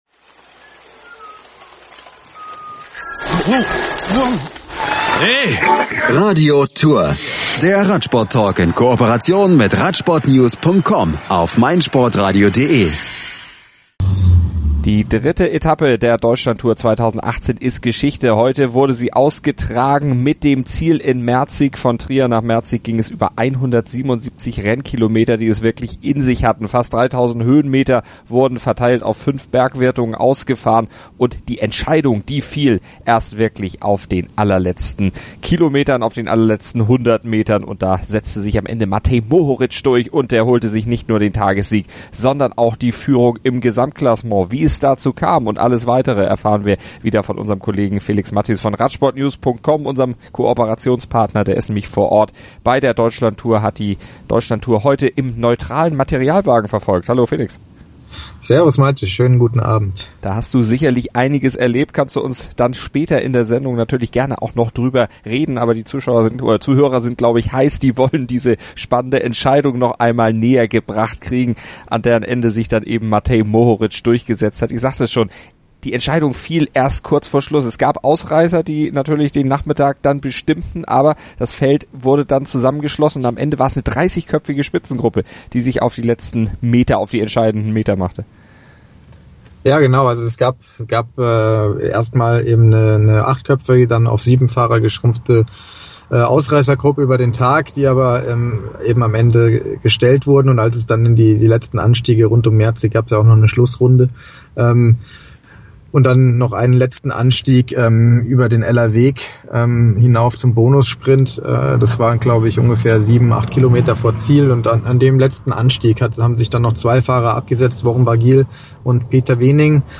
Außerdem haben sie den gestürzten Lennart Kämna, den entthronten Maximilian Schachmann sowie den heute relativ stressfreien Pascal Ackermann im Interview.